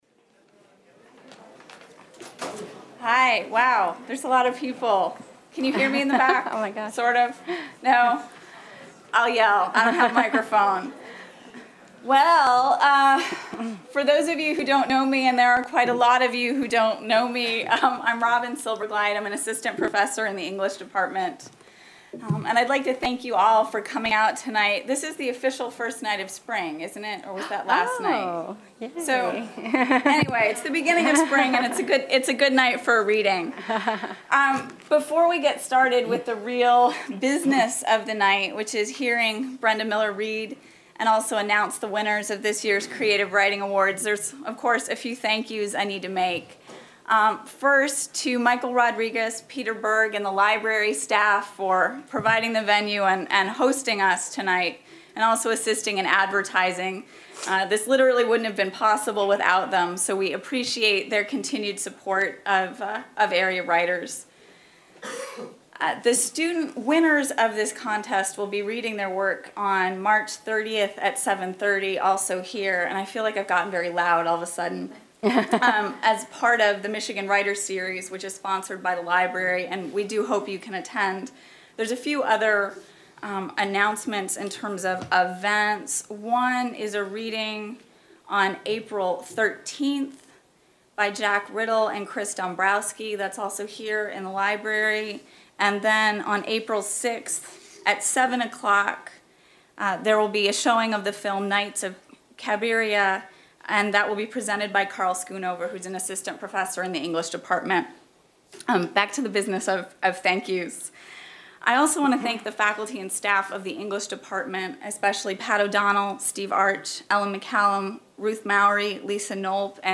Held in the MSU Main Library.